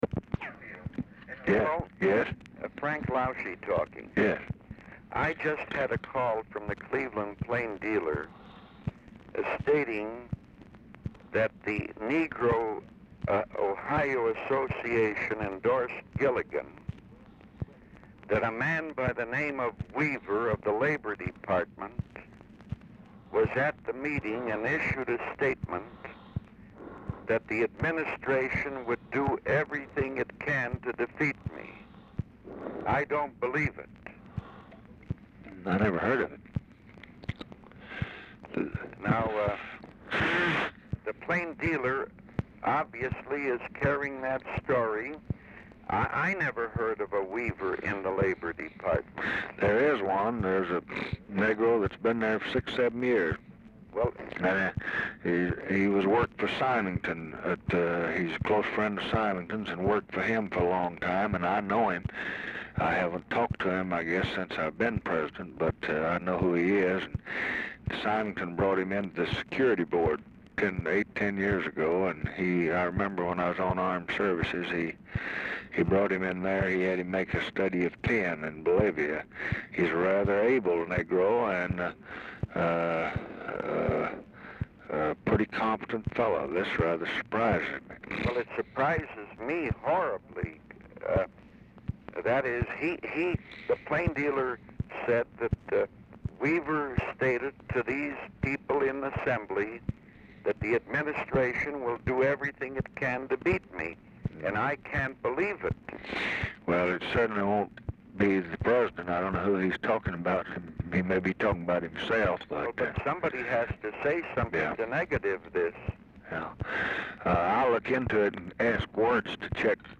Telephone conversation # 12614, sound recording, LBJ and FRANK LAUSCHE, 1/28/1968, 4:28PM | Discover LBJ
Format Dictation belt
Location Of Speaker 1 Mansion, White House, Washington, DC
Specific Item Type Telephone conversation